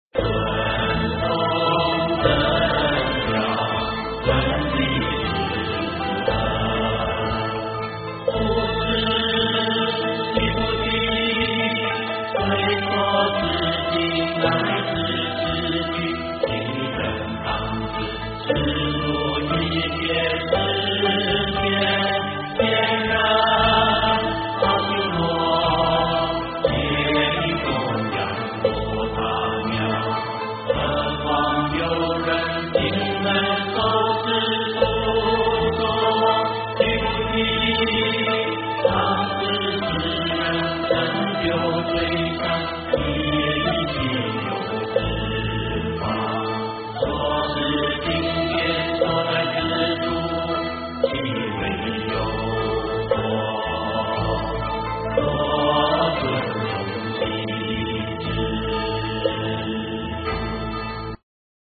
金刚经-尊重正教分第十二 - 诵经 - 云佛论坛